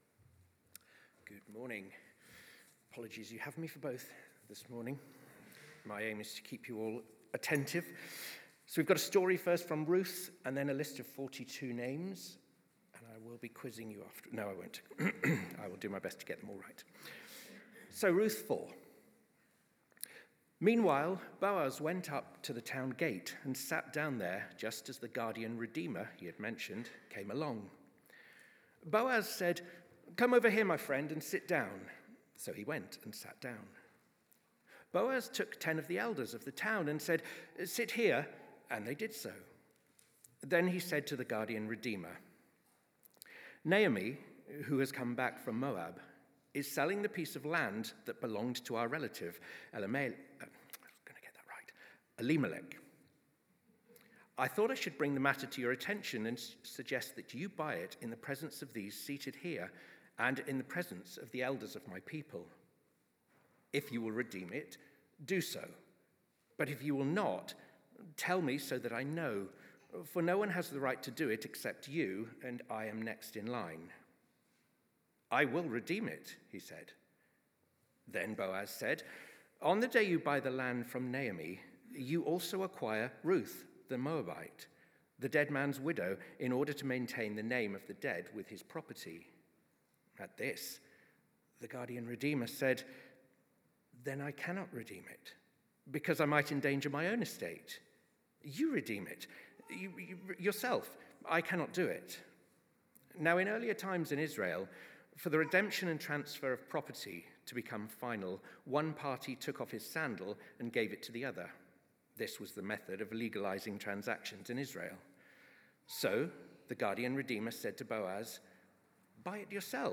Emmanuel Church Sermons